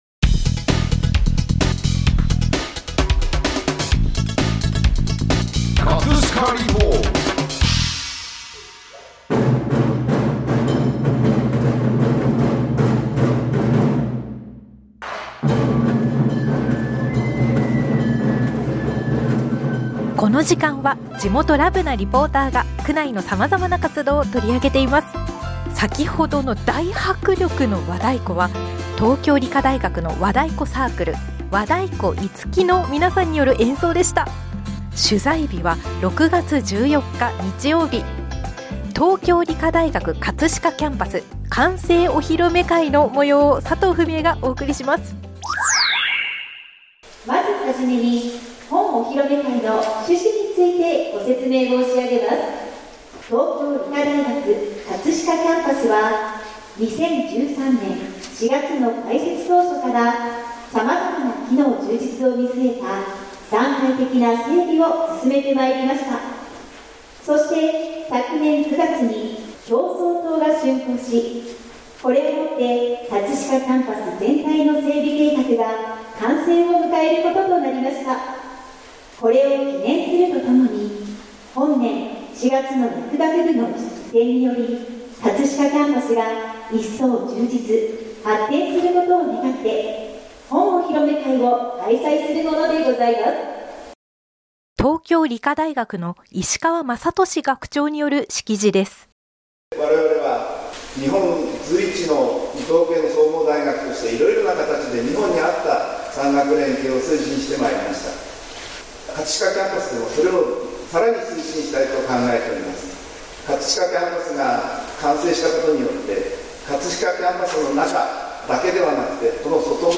【葛飾リポート】 葛飾リポートでは、区内の様々な活動を取り上げています。